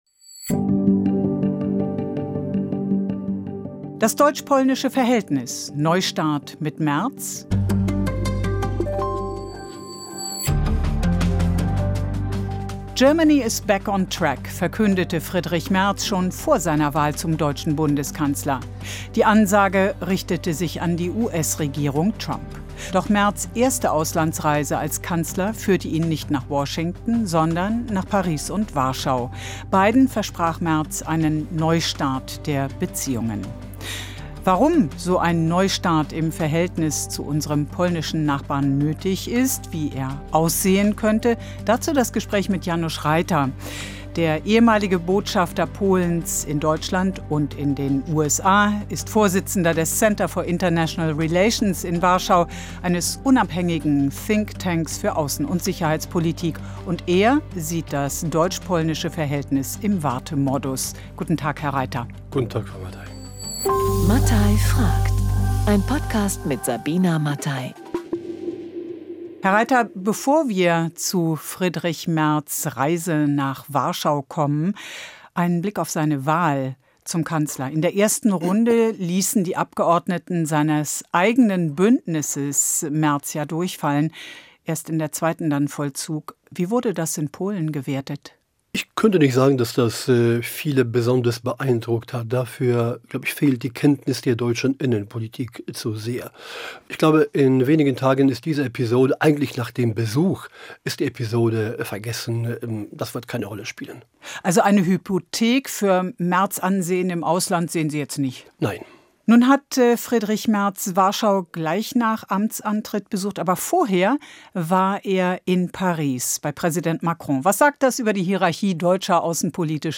Ein Gast, eine These, jede Menge Fragen.